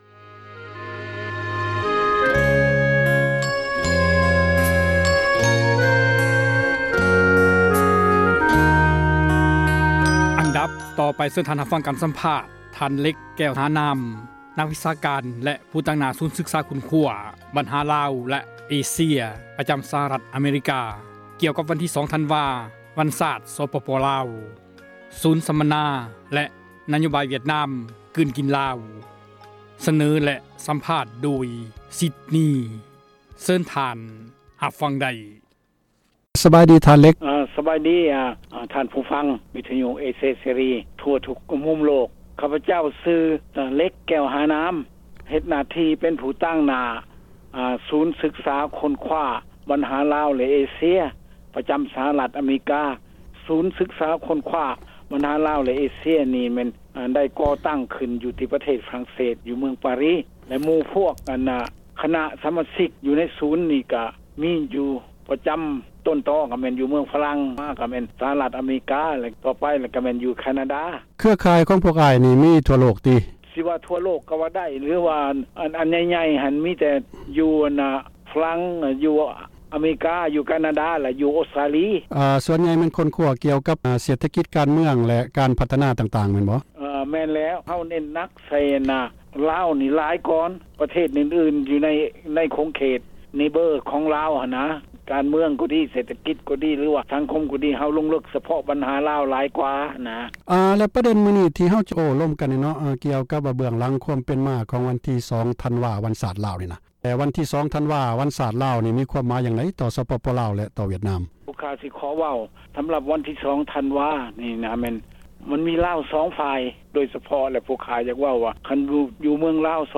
ສຳພາດນັກວິຊາການ
ການ ສຳພາດ